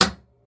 machinebutton.mp3